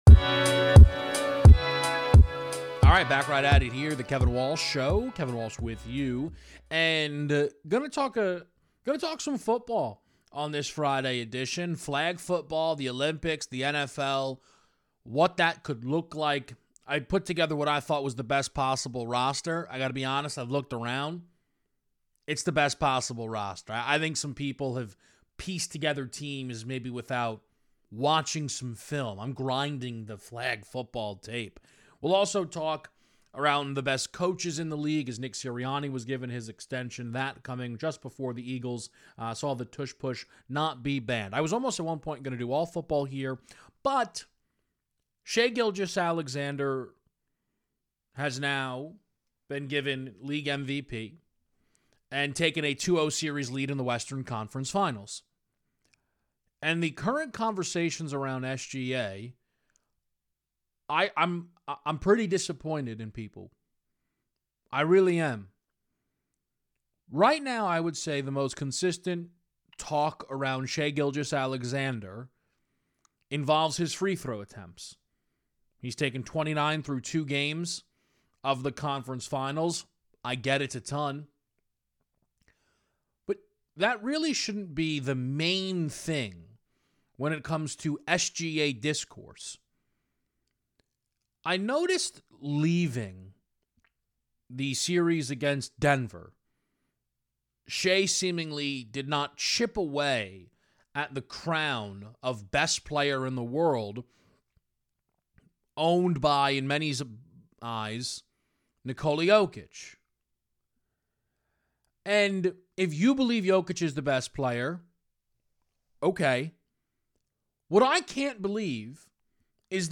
(IT GETS LOUD)